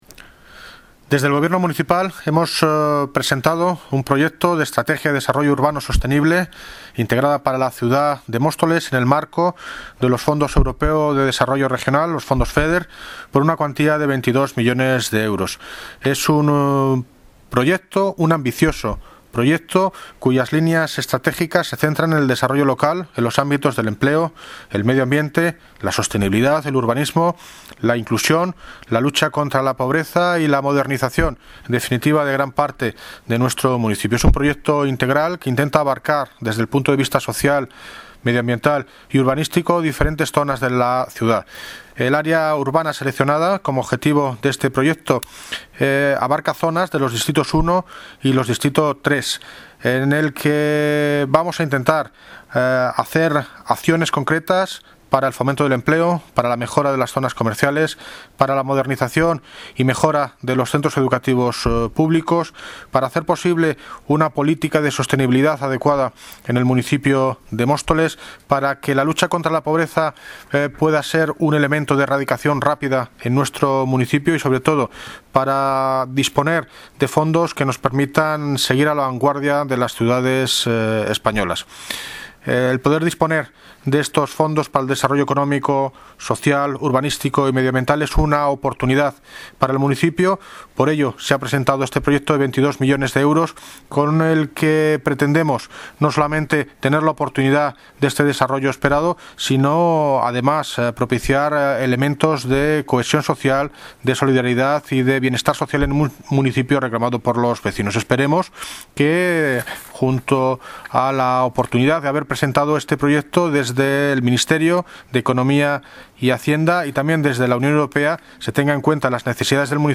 Audio - David Lucas (Alcalde de Móstoles) sobre Proyecto Estrategia de Desarrollo Urbano